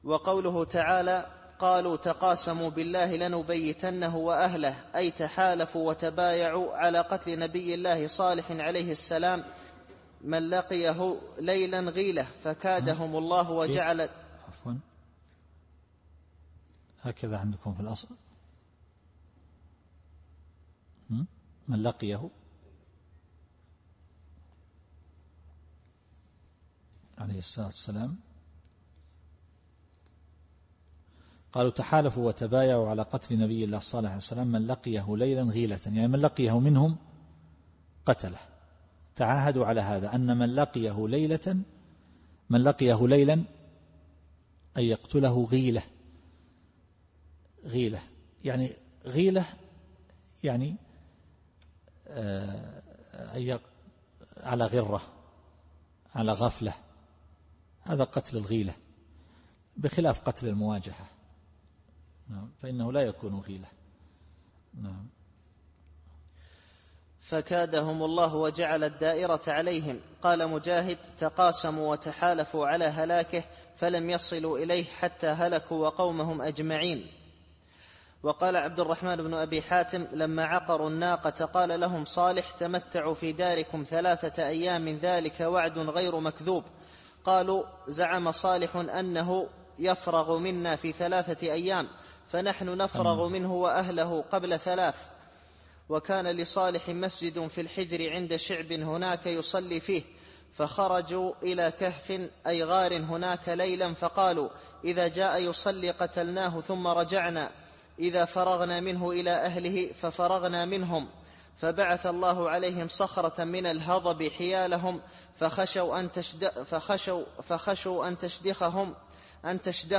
التفسير الصوتي [النمل / 49]